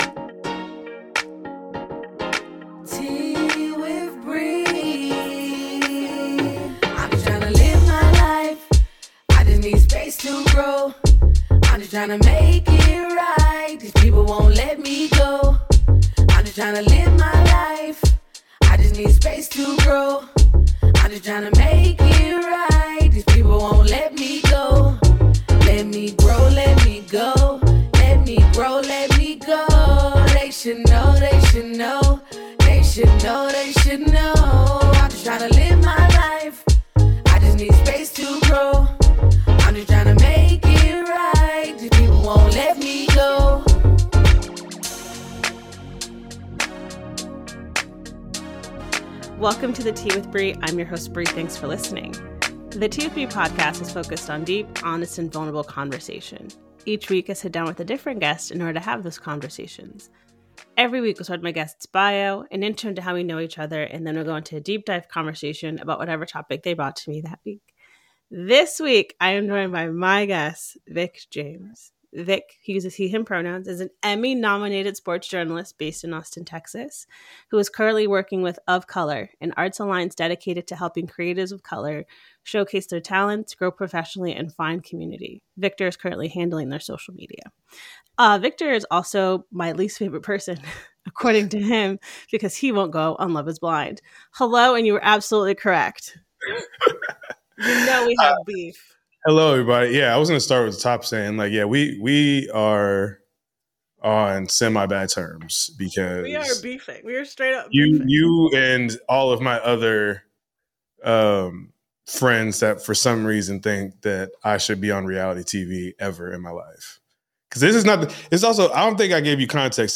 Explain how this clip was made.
----- This podcast was recorded via Riverside FM.